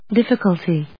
dif・fi・cul・ty /dífɪk`ʌlti, ‐kəl‐‐kəl‐/
• / dífɪk`ʌlti(米国英語)